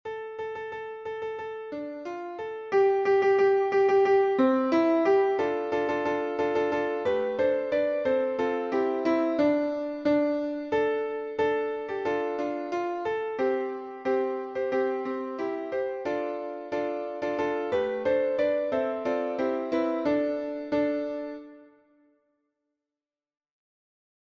- Œuvre pour choeur à 4 voix d’hommes (TTBB) + soliste
MP3 version piano